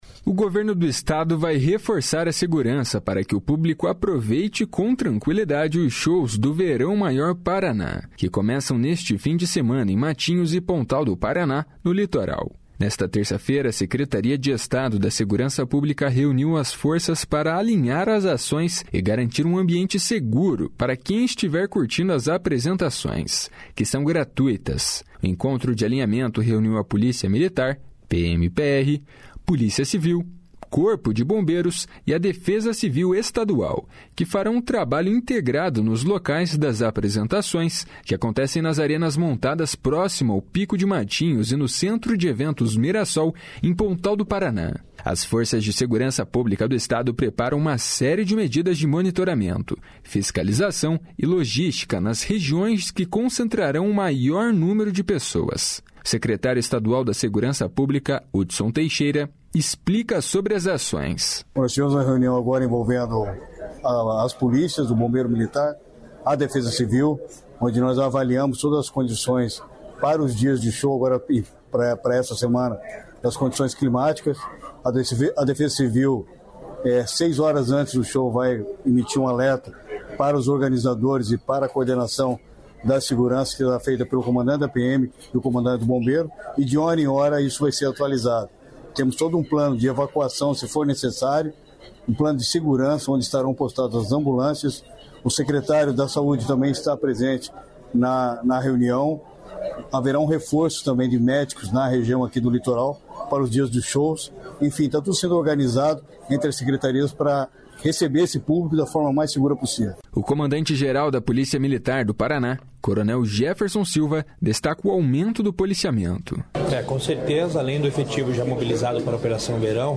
As forças de segurança pública do Estado preparam uma série de medidas de monitoramento, fiscalização e logística nas regiões que concentrarão o maior número de pessoas. O secretário estadual da Segurança Pública, Hudson Teixeira, explica sobre as ações.
O comandante-geral da Polícia Militar do Paraná, coronel Jefferson Silva, destaca o aumento do policiamento.